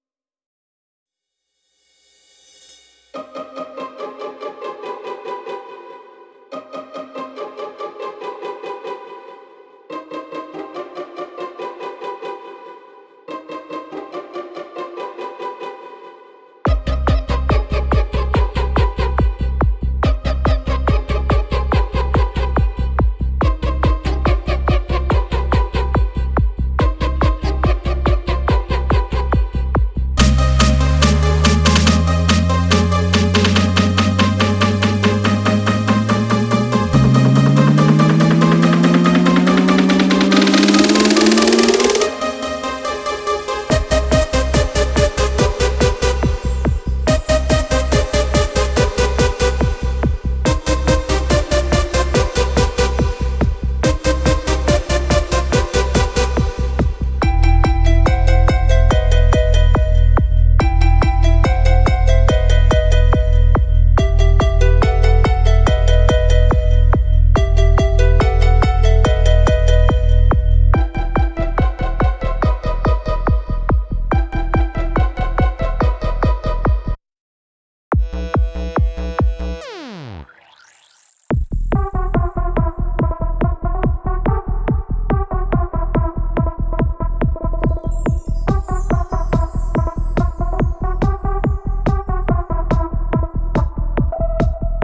קטע קצר שהולך ומתפתח איכותי ומעניין ועבודת אולפן מקצועית